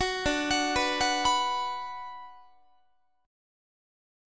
Listen to D6 strummed